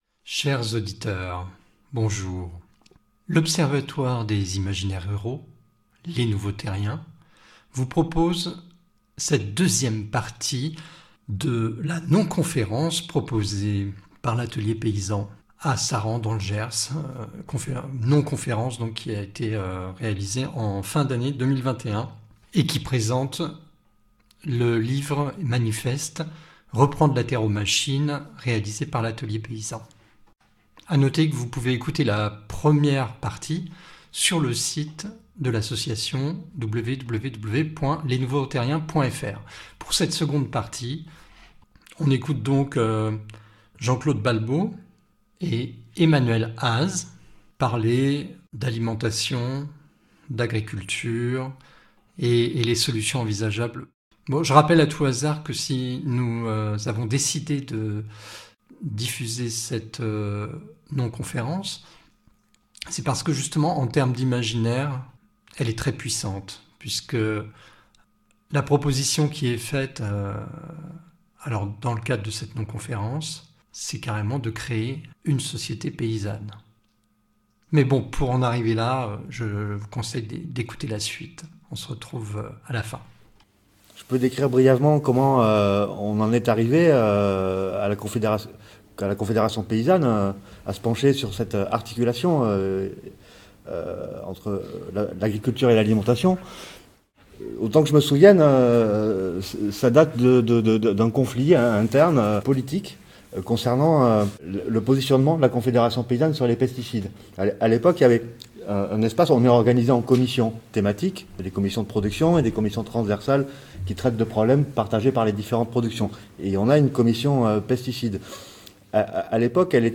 Vous pouvez écouter cette conférence en deux parties :